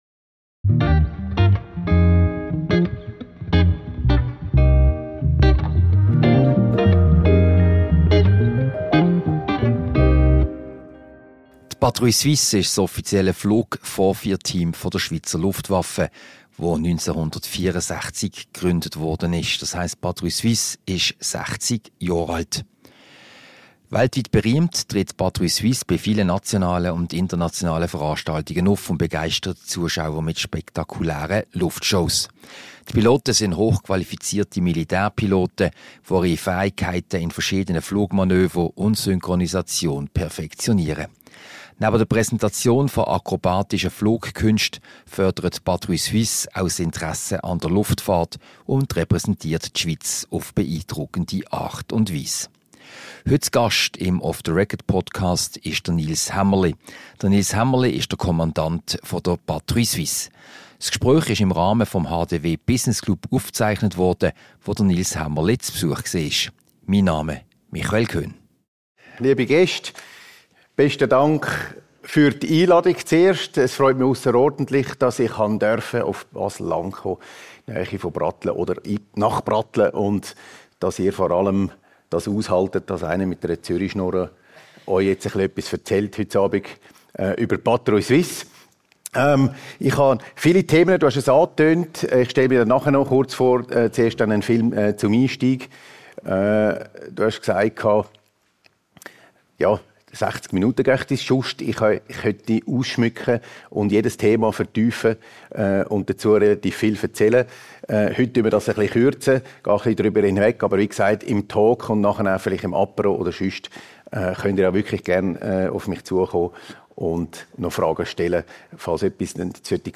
Diese Podcast-Ausgabe wurde am HDW Business Club Dinner vom Dienstag, 22. Oktober 2024, im Haus der Wirtschaft aufgezeichnet.